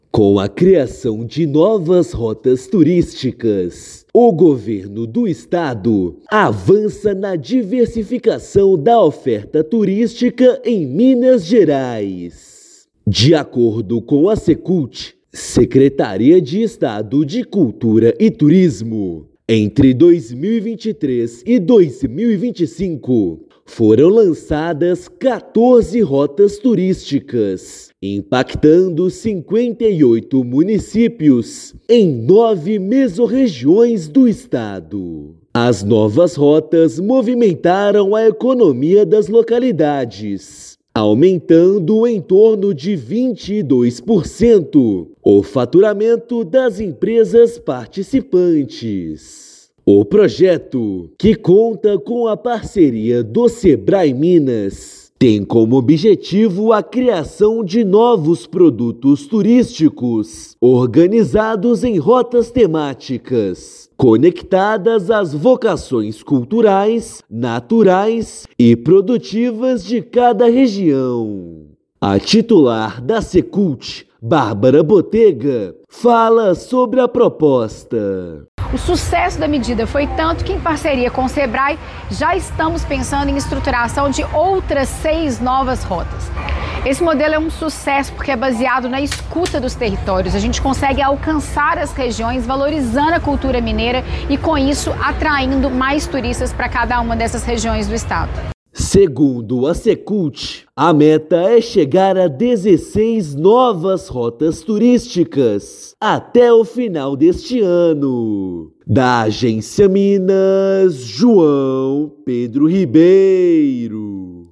Rotas transformam vocações territoriais em produtos turísticos, fortalecem economias locais e revelam novas formas de viver a mineiridade. Ouça a matéria de rádio.